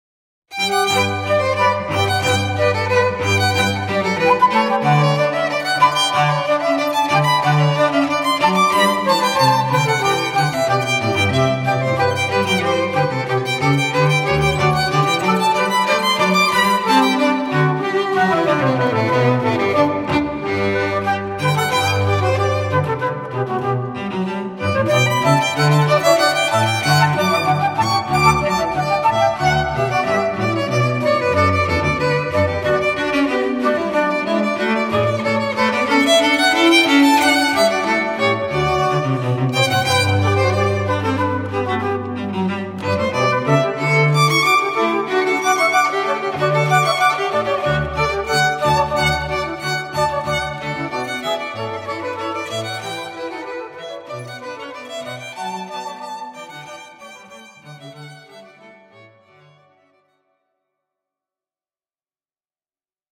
Sapphire Trio create a warm ambience with their easy-listening combination of cello, violin and flute: the ideal backdrop to set your wedding against.
Classical Trio with Flute
Cello, Violin, Flute
sapphire-brandenburg-concerto.mp3